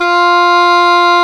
WND OBOE F4.wav